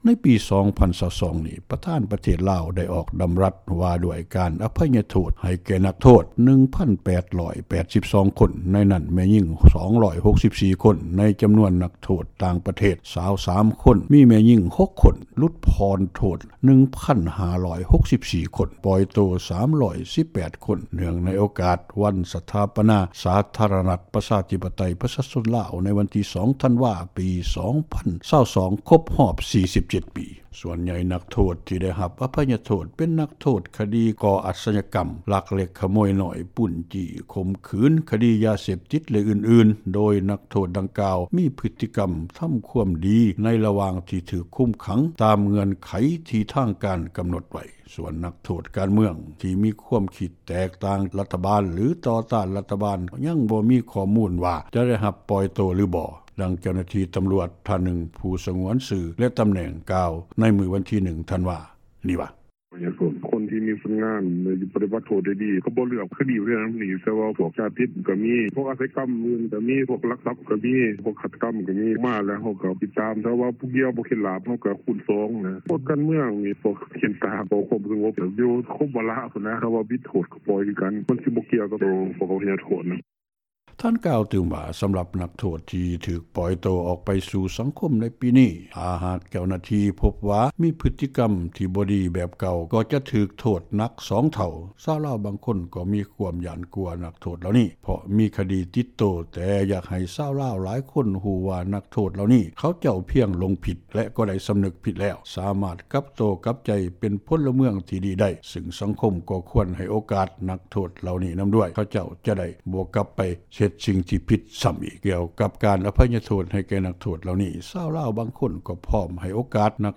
ນັກຂ່າວ ພົລເມືອງ
ດັ່ງເຈົ້າໜ້າທີ່ຕຳຣວດ ຜູ້ທີ່ສງວນຊື່ ແລະຕຳແໜ່ງ ທ່ານນຶ່ງກ່າວຕໍ່ວິທຍຸ ເອເຊັຽ ເສຣີ ໃນວັນທີ 1 ທັນວາ ປີ 2022 ນີ້ວ່າ:
ດັ່ງຊາວລາວ ຄົນນຶ່ງເວົ້າວ່າ:
ດັ່ງເຈົ້າໜ້າທີ່ ເຮັດວຽກຝຶກອາຊີພ ໃຫ້ແກ່ຄົນງານລາວ ທ່ານນຶ່ງເວົ້າວ່າ: